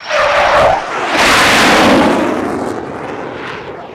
F-104 Pass By Head Only